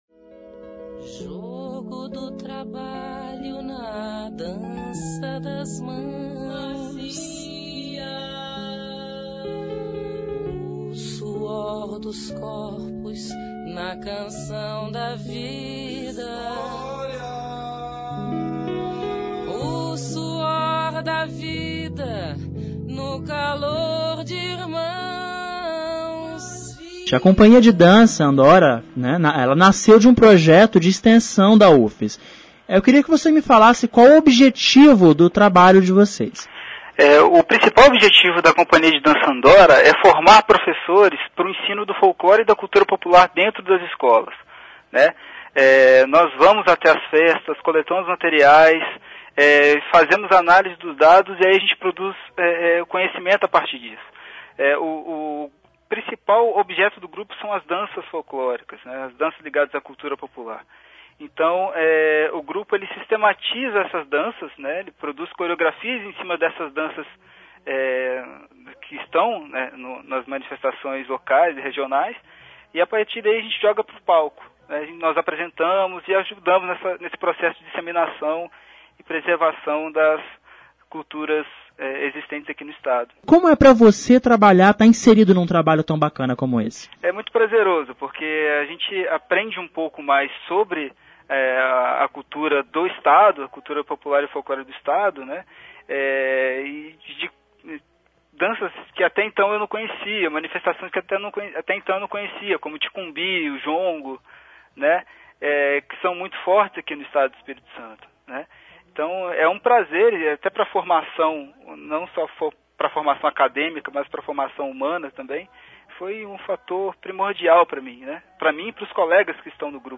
entrevista_final_siteeee.mp3